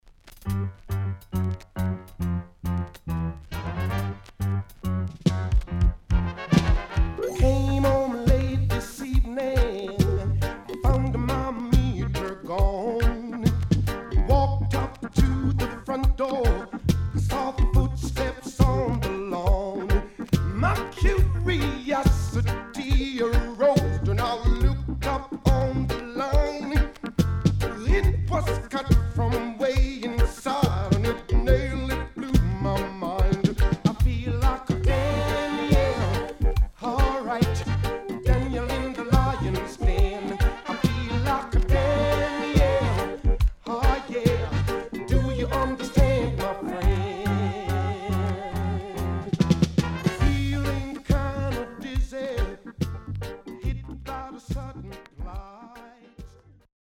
渋Vocal